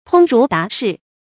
通儒達士 注音： ㄊㄨㄙ ㄖㄨˊ ㄉㄚˊ ㄕㄧˋ 讀音讀法： 意思解釋： 指知識淵博、明智達理的學者。